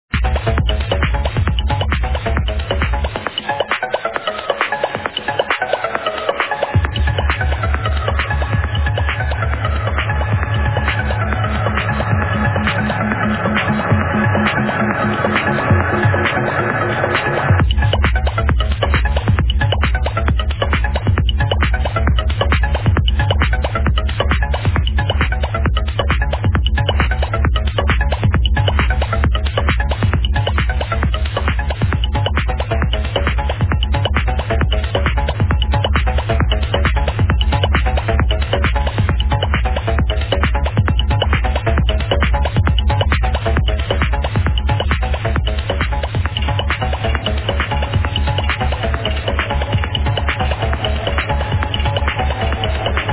Techno track ID5
Also a banging track to ID.